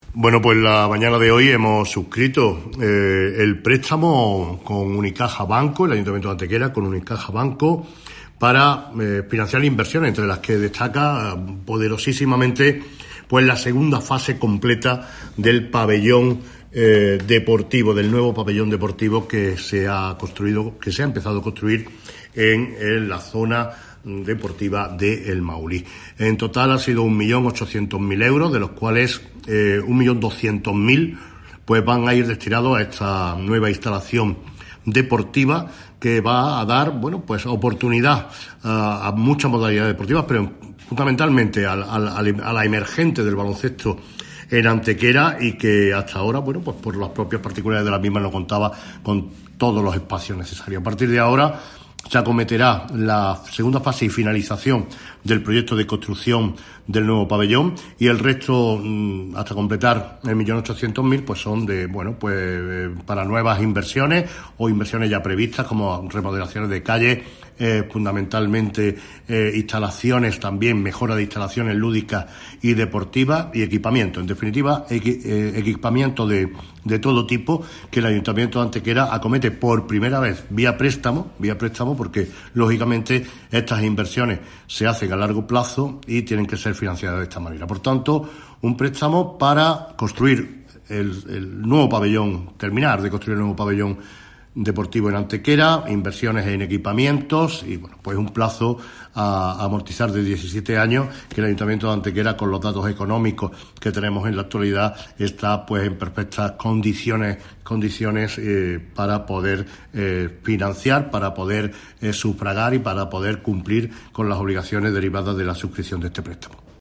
Cortes de voz M. Barón 798.49 kb Formato: mp3